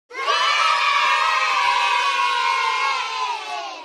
yay group of kids cheering Meme Sound Effect
yay group of kids cheering.mp3